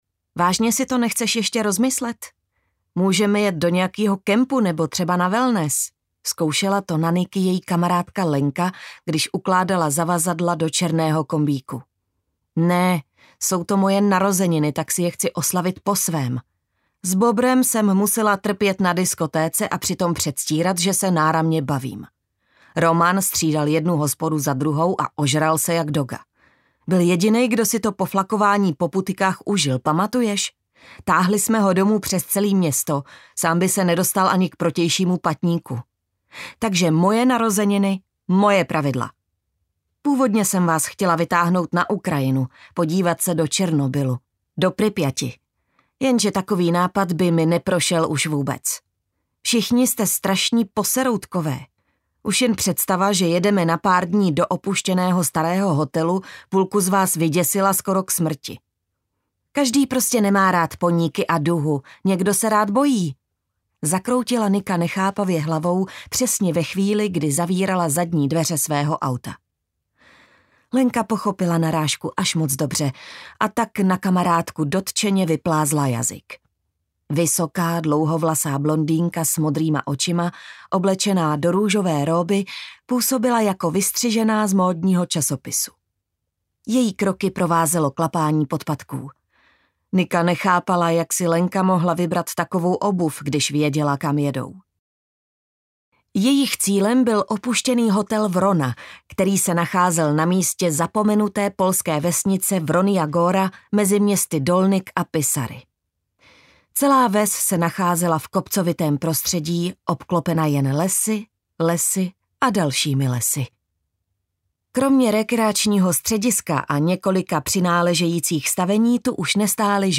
Hotel času audiokniha
Ukázka z knihy